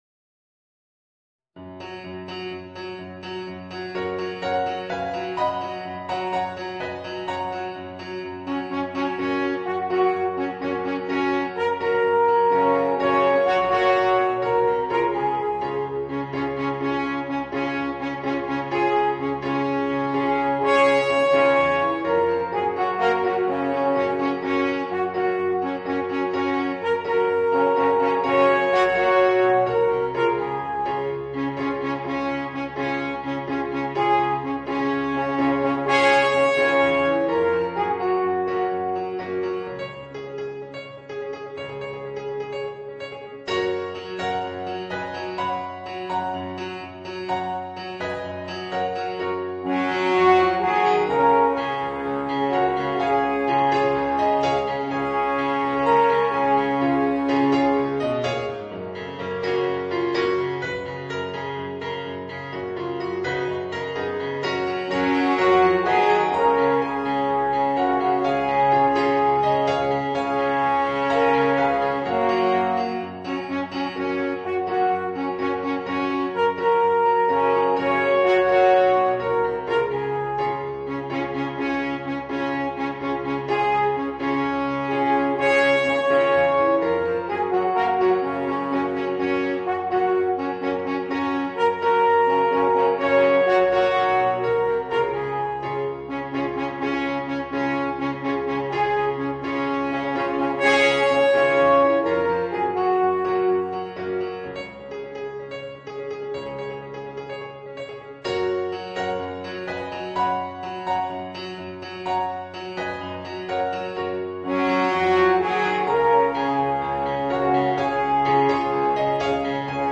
Voicing: 2 Alphorns and Piano